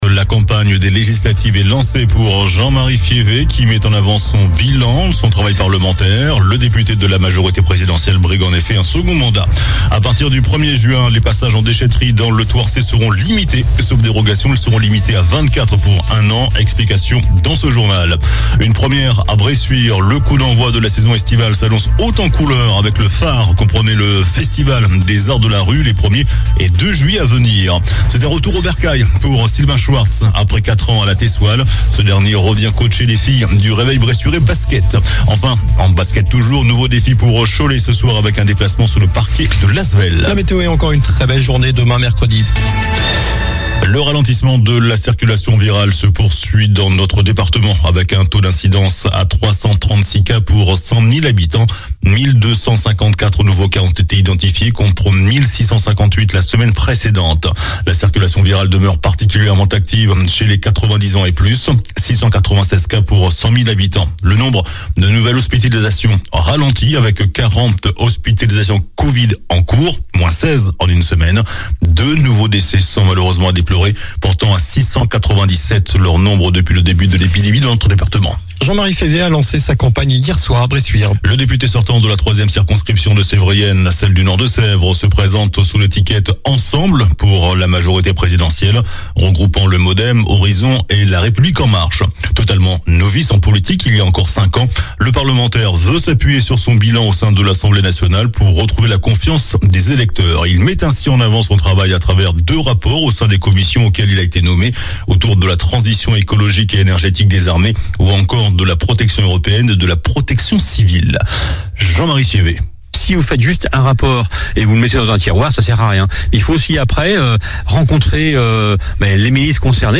COLLINES LA RADIO : Réécoutez les flash infos et les différentes chroniques de votre radio⬦
L'info près de chez vous